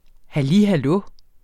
Udtale [ haˈli haˈlɔ ]